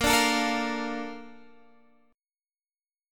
A#M7 chord